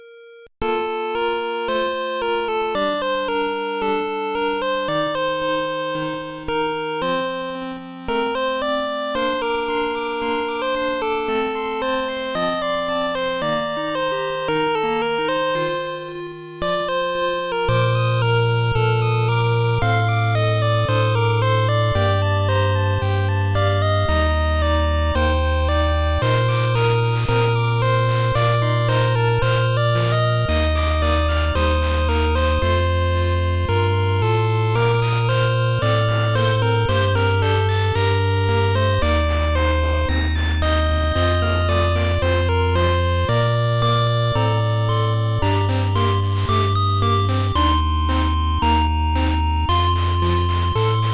8-Bit